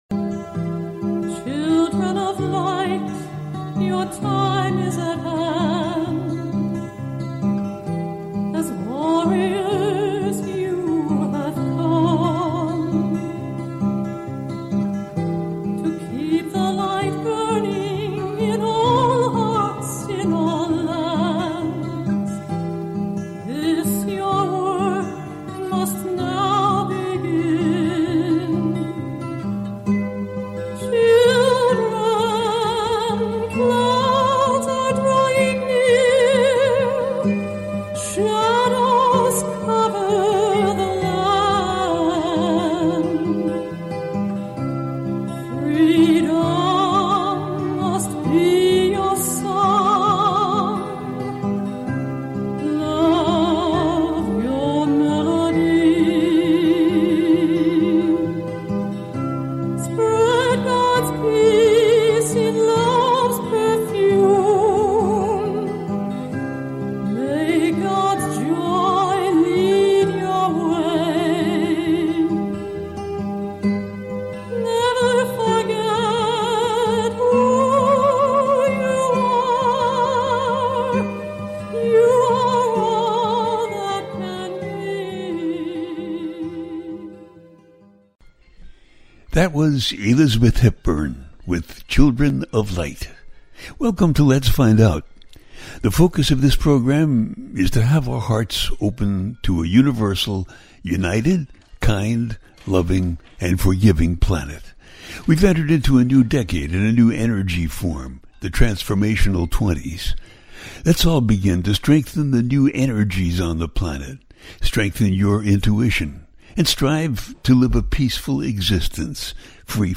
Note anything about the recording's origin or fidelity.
The listener can call in to ask a question on the air.